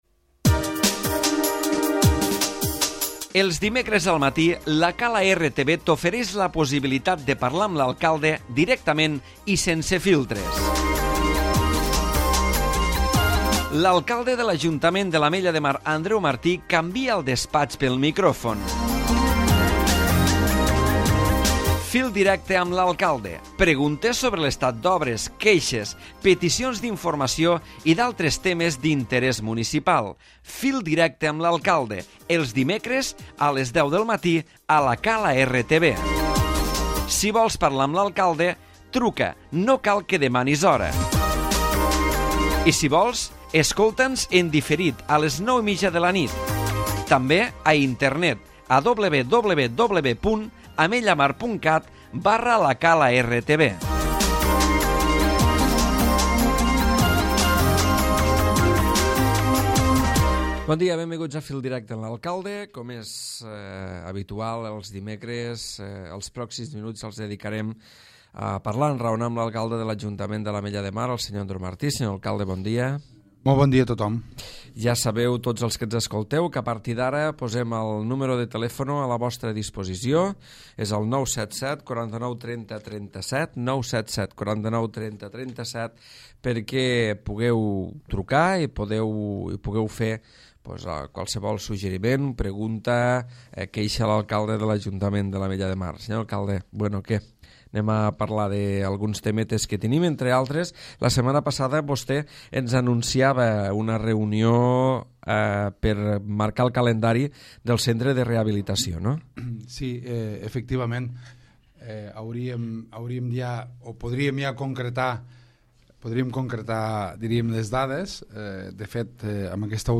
L'Alcalde Andreu Martí ha parlat de les gestions perquè es pugui sintonitzar Esports 3 a l'Ametlla i també per a la millora dels serveis d'Adif a l'estació. A més a anunciat el començament aquesta setmana de les obres del passeig marítim de la Cova Gran i l'inici de l'activitat al centre de rehabilitació pel dia 1 d'abril.